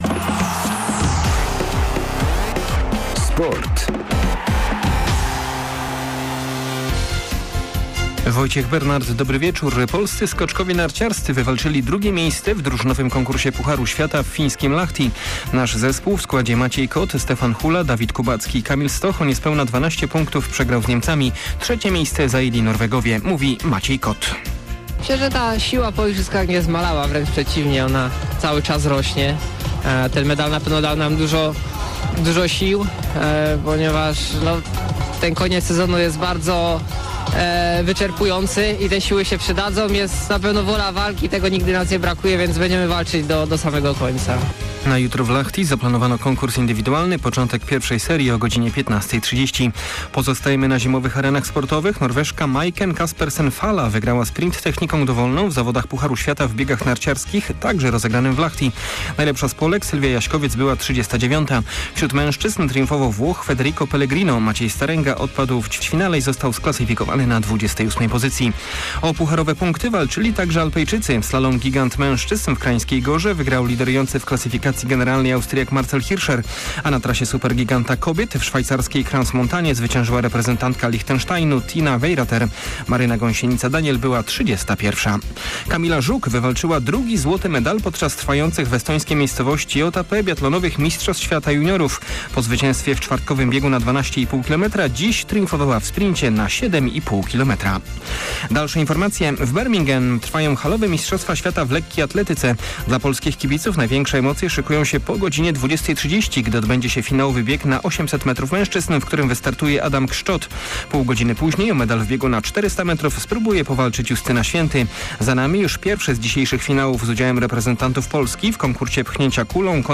03.03 serwis sportowy godz. 19:05